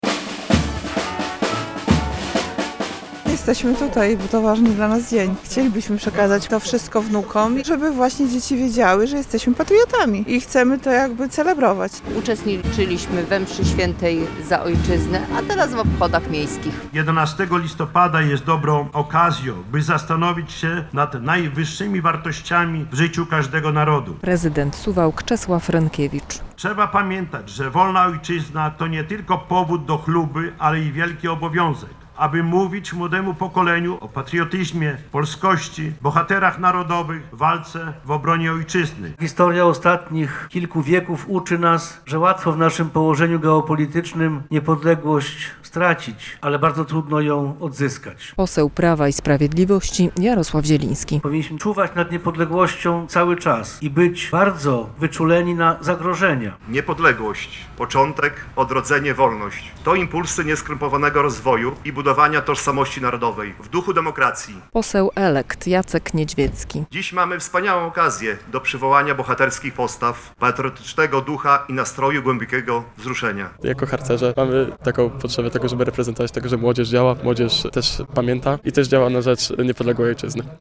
Uroczystość oficjalna z udziałem mieszkańców i zaproszonych gości odbyła się pod pomnikiem Marszałka Józefa Piłsudskiego. Tam zebrani wspólnie odśpiewali hymn państwowy, wysłuchali okolicznościowych przemówień i oddali hołd wszystkim tym, którzy walczyli o wolną i niepodległą Polskę.